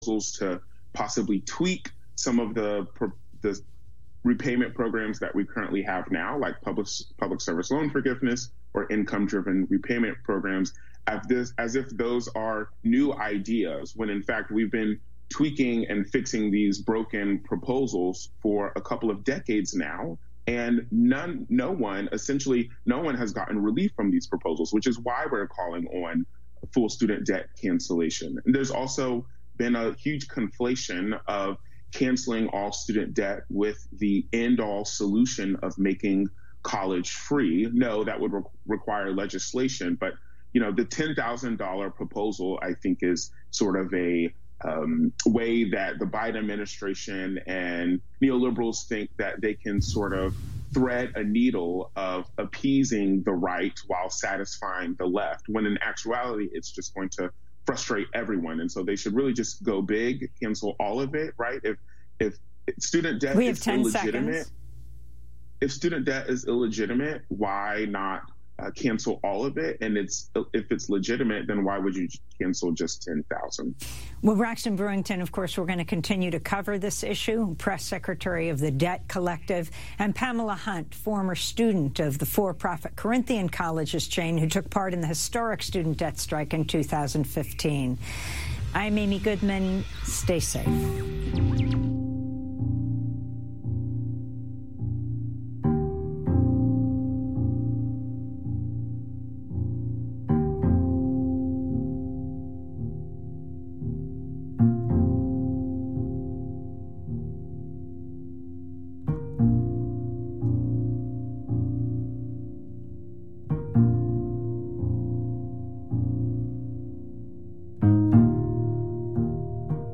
The Gap is talk radio aimed at bridging the generational divide.